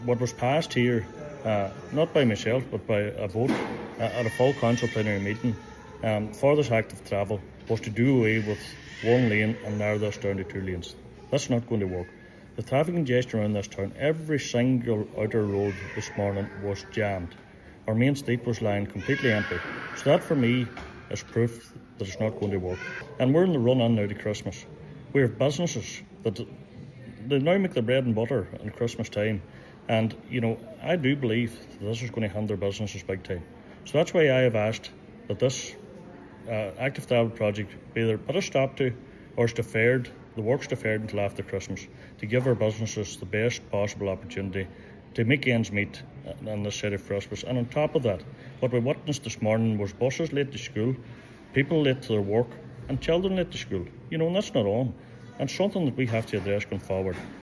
Councillor Kelly says the project needs to at least be postponed until after Christmas: